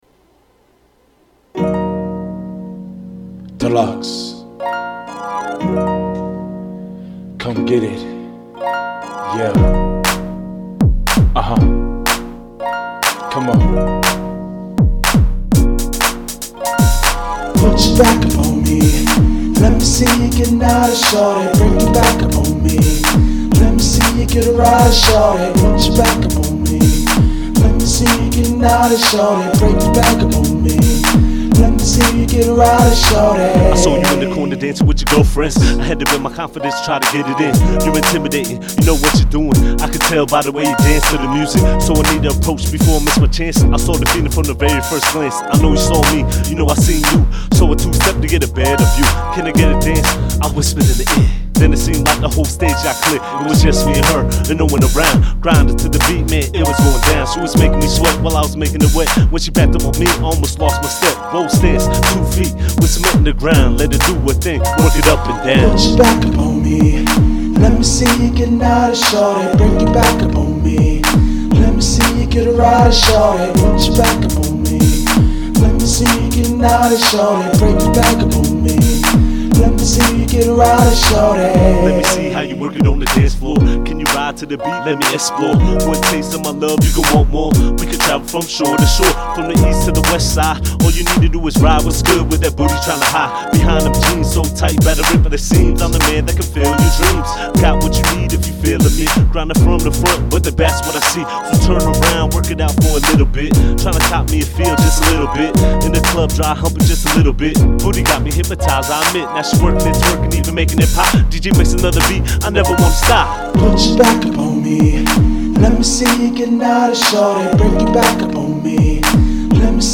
Now this is a beat I made where I sang the chorus and a buddy of mine rapped the verses.